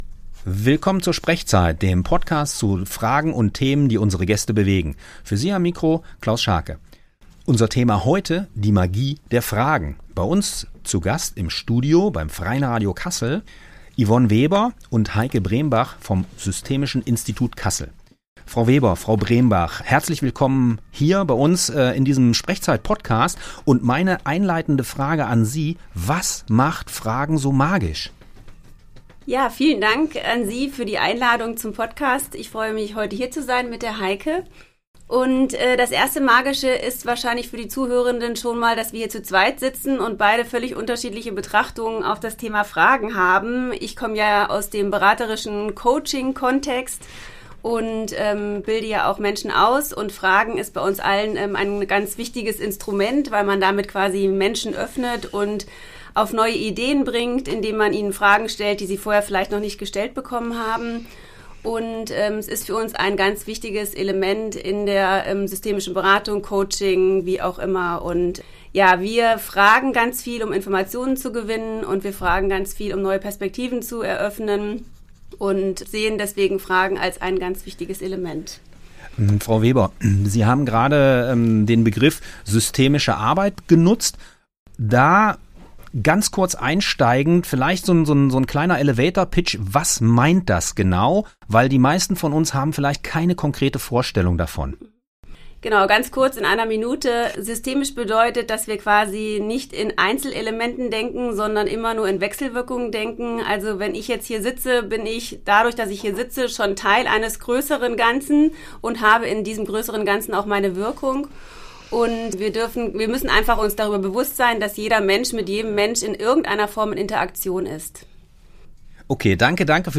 In dieser SprechZeit-Folge geht es um die besondere Kraft des Fragens – und darum, warum echtes Nicht-Wissen kein Mangel ist, sondern ein Schlüssel zu neuen Erkenntnissen. Es geht um das bewusste Aushalten von Unwissen und die Kunst, durch Fragen echte Tiefe zu erzeugen. Ein inspirierendes Gespräch für alle, die beraten, begleiten – oder einfach mit mehr Neugier durchs Leben gehen wollen.
Dieser SprechZeit-Podcast wurde am 21.3.2025 beim Freien Radio Kassel aufgezeichnet.